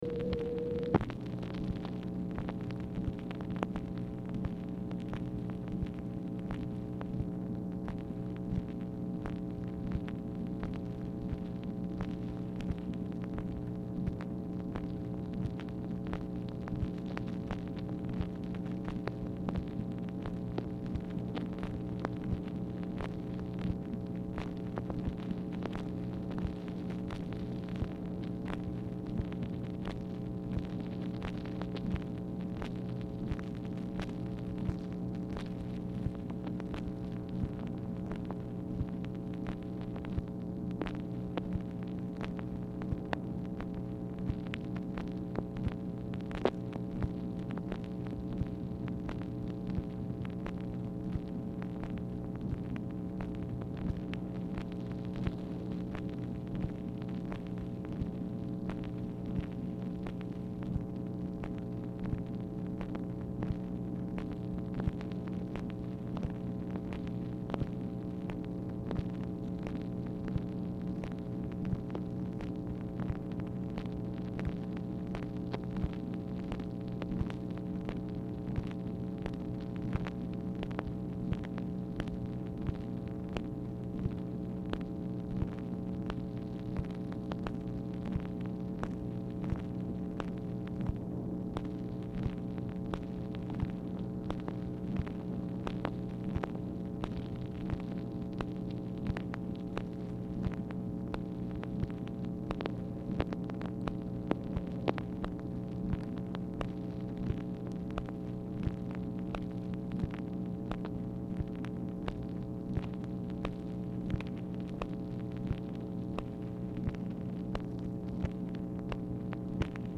Telephone conversation # 7670, sound recording, MACHINE NOISE, 5/14/1965, time unknown | Discover LBJ
Format Dictation belt
Specific Item Type Telephone conversation